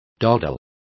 Complete with pronunciation of the translation of dawdled.